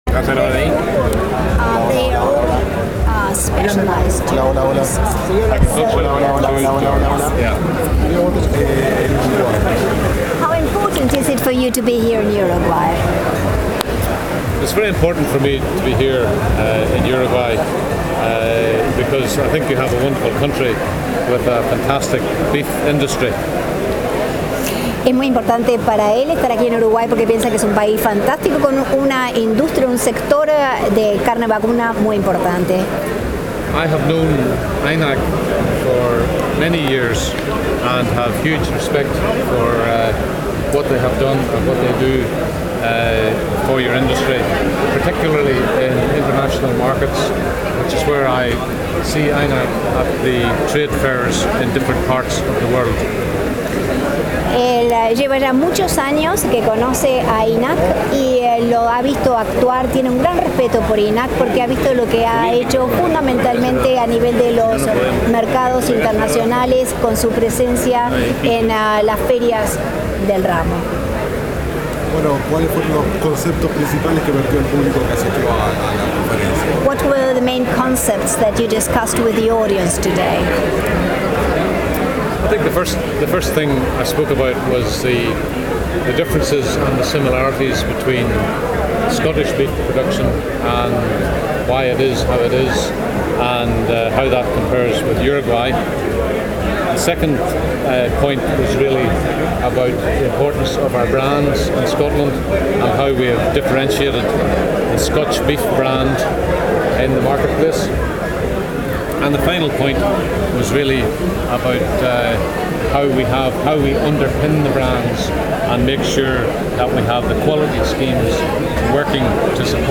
Conferencia Internacional